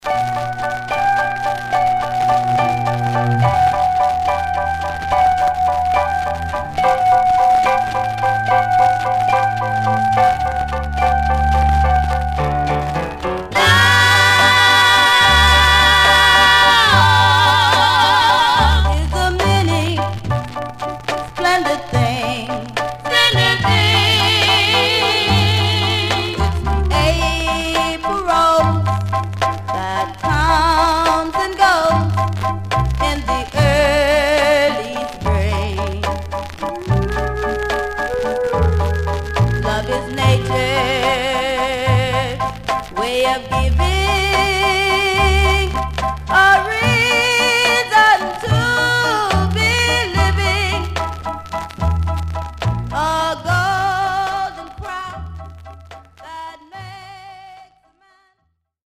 Mono
Black Female Group